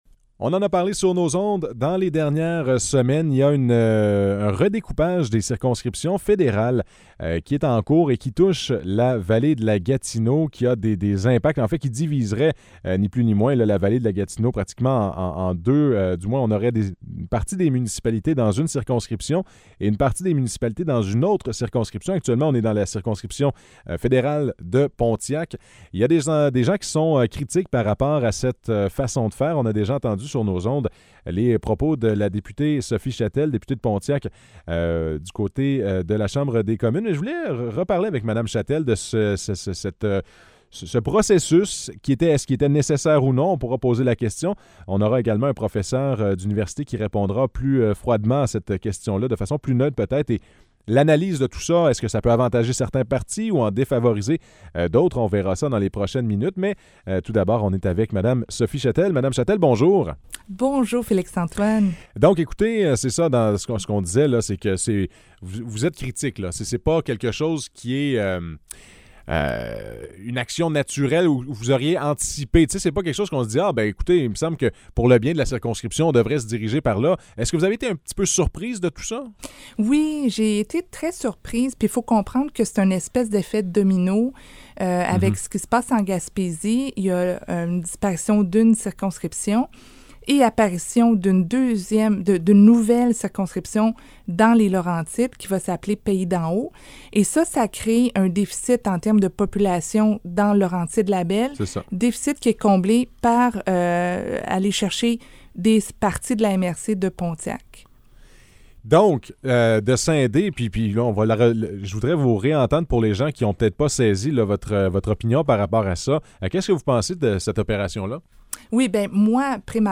Entrevue avec Sophie Chatel, députée fédérale de Pontiac
Entrevues